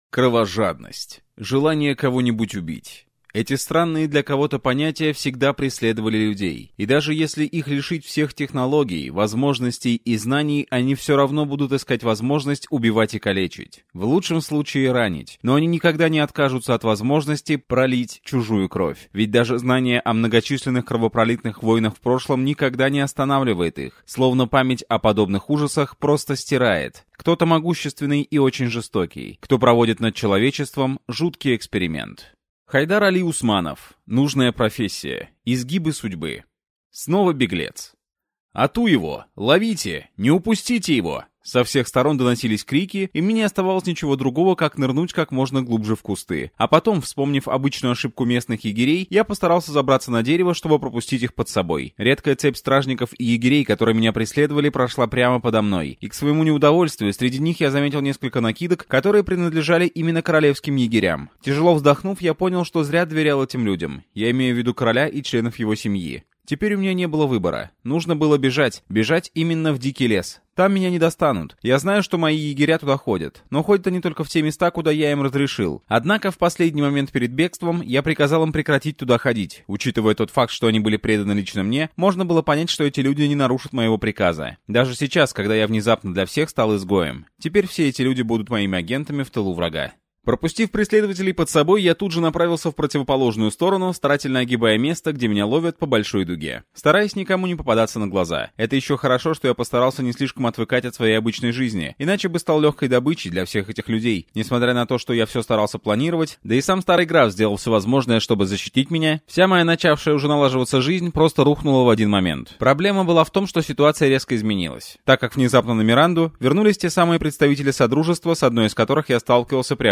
Аудиокнига Нужная профессия. Изгибы судьбы | Библиотека аудиокниг